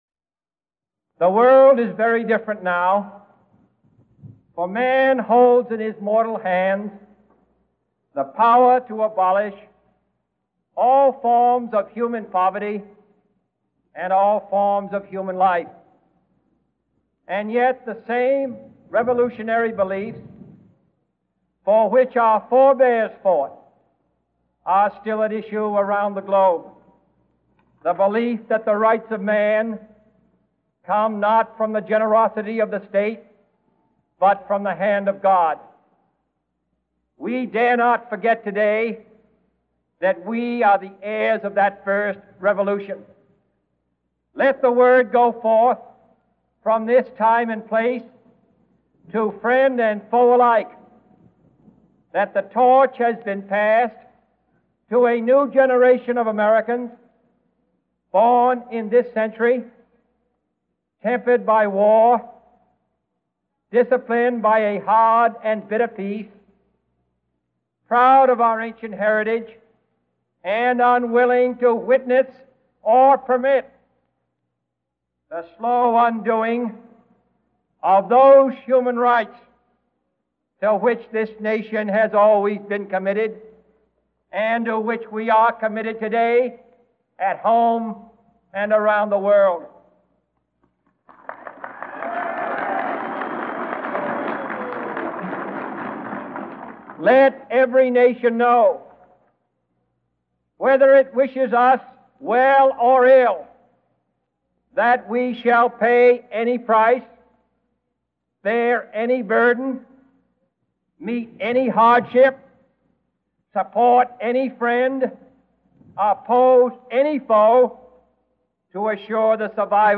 Inaugural address, 20 January 1961